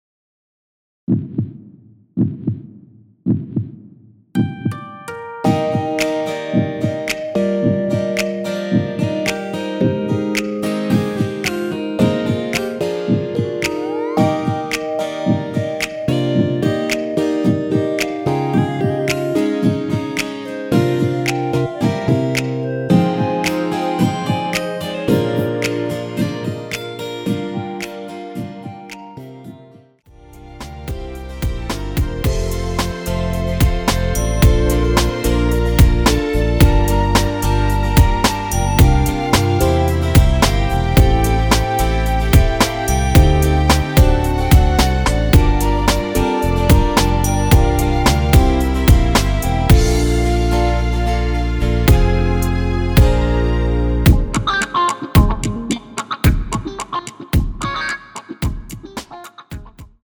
원키에서(+2)올린 멜로디 포함된 MR입니다.
Eb
앞부분30초, 뒷부분30초씩 편집해서 올려 드리고 있습니다.
중간에 음이 끈어지고 다시 나오는 이유는